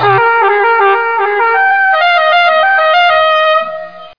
Anstatt einen Text vorlesen zu lassen, könntest ja auch einen schrecklichen Ton abspielen, den du auch bei normaler Lautstärke nicht überhörst.
Trumpet.wav